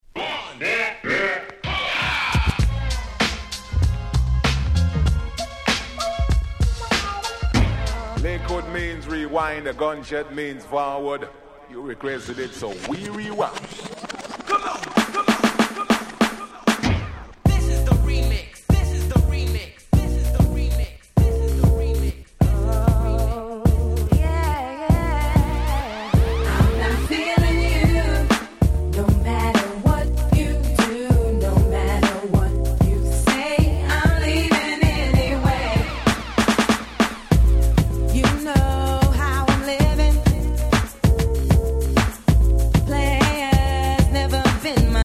97' Nice Reggae Remix !!
ダンスホールレゲエ